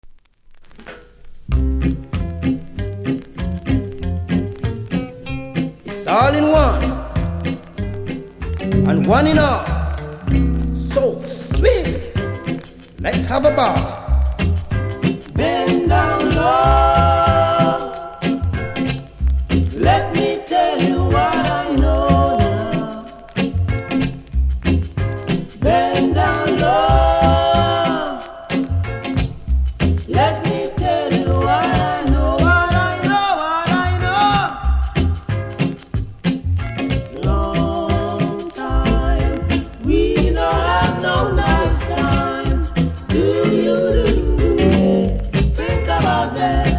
両面ボブの名曲メドレー♪
キズは両面多めですが音にはそれほど影響されていないので試聴で確認下さい。